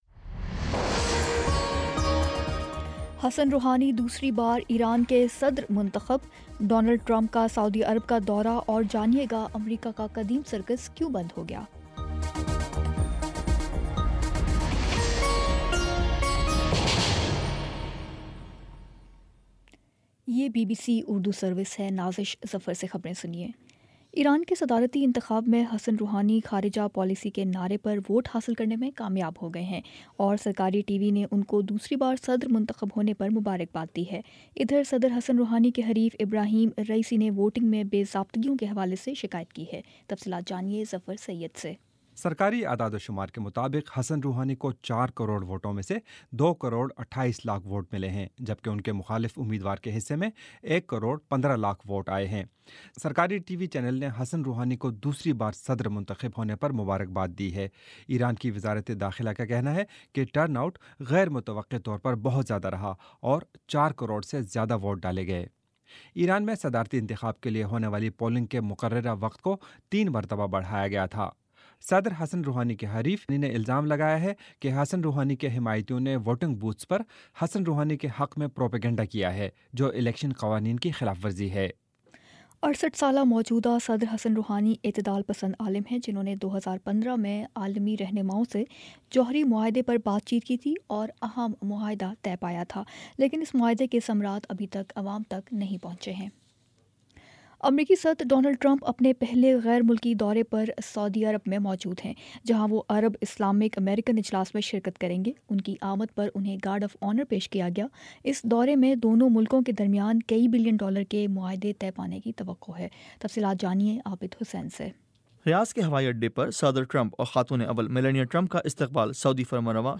مئی 20 : شام چھ بجے کا نیوز بُلیٹن